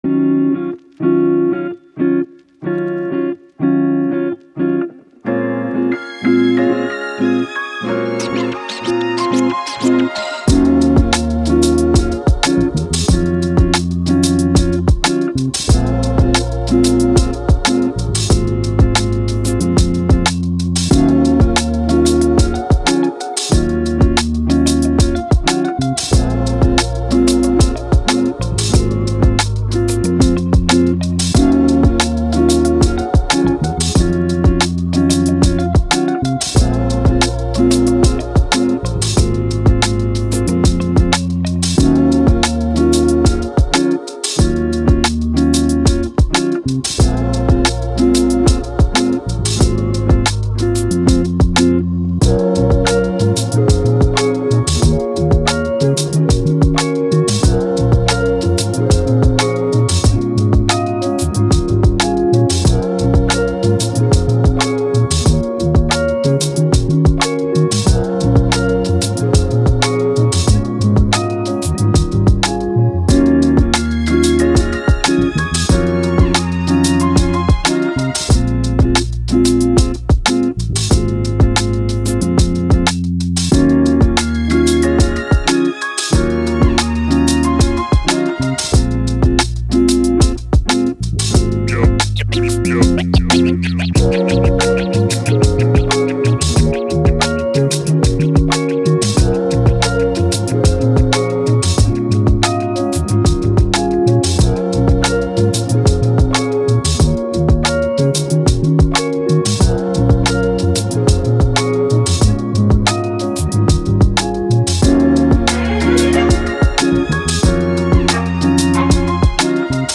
Soul, Chill, Joy